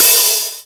063 - HH-5O.wav